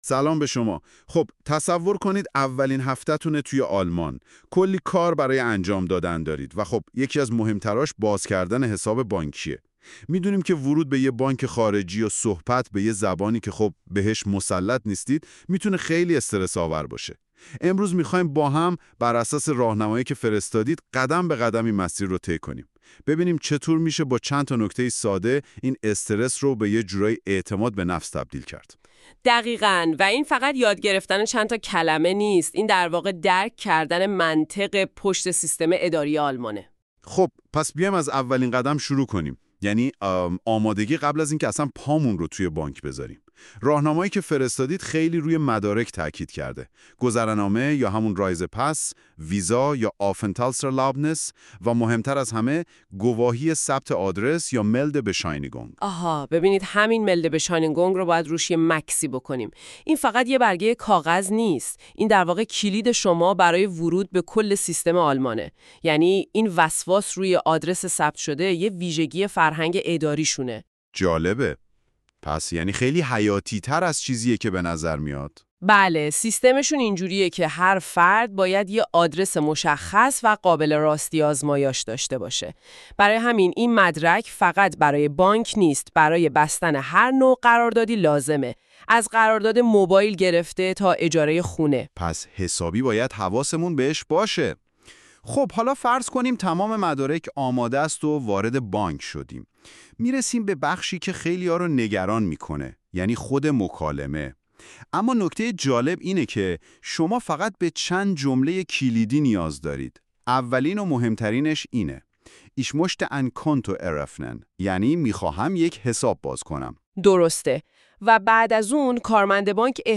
German-Bank-Conversation.mp3